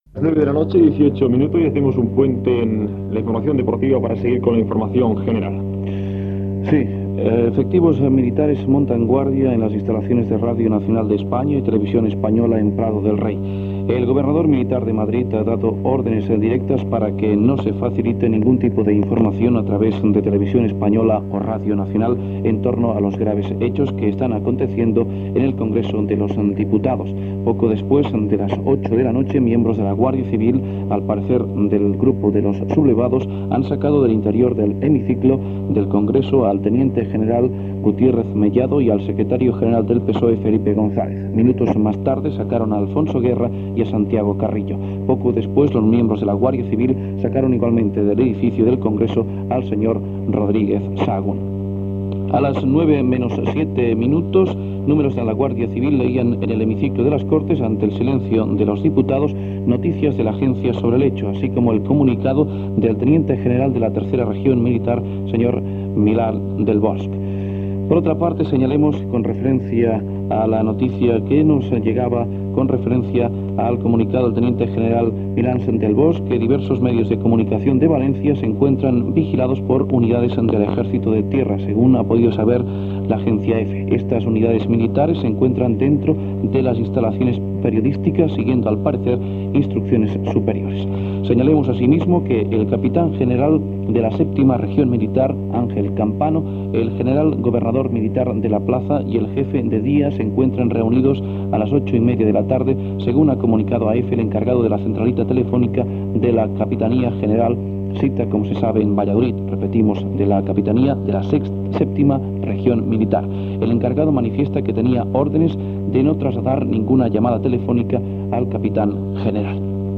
Entrevista telefònica a l'alcalde de Sabadell, Antoni Farrés.
Informatiu